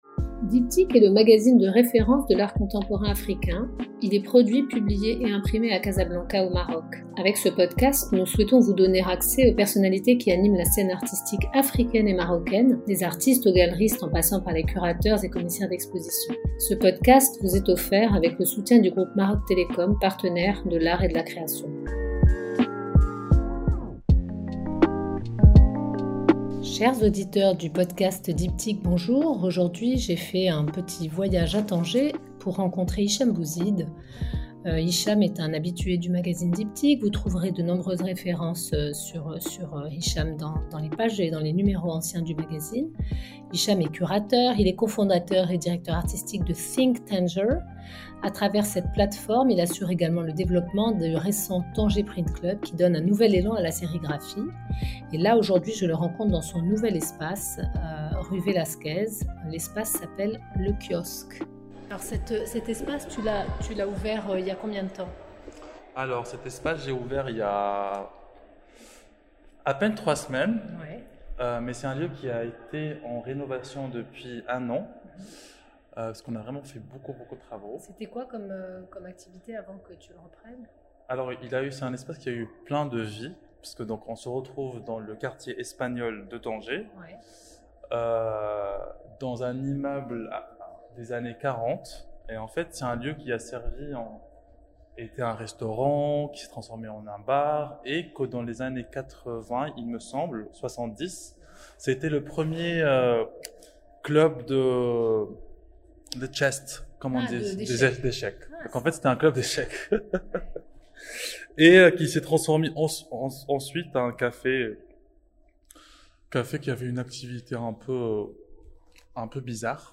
Cet épisode a été enregistré en Juillet 2023 au Kiosk à Tanger, avec Maroc Télécom, partenaire des arts et de la création.